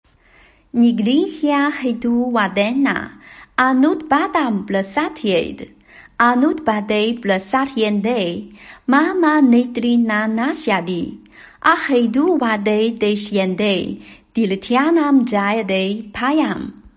楞伽经3.91朗读.mp3